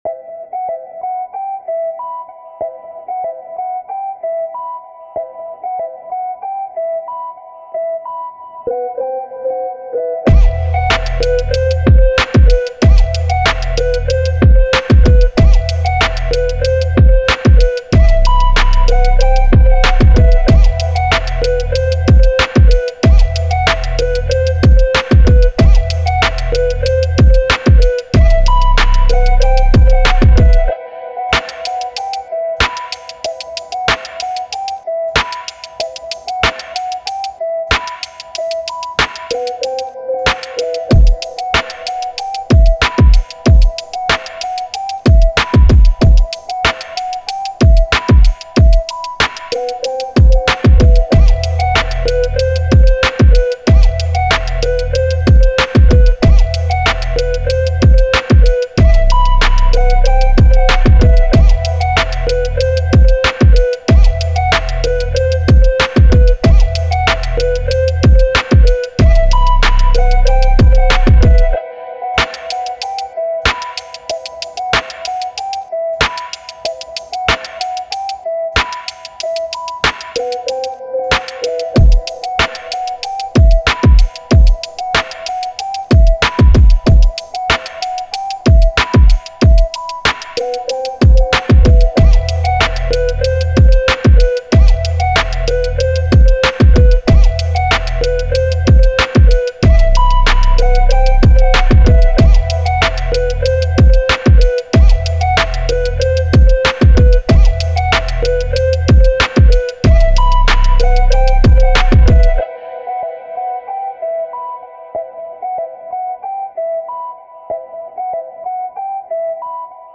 Hip Hop
E Maj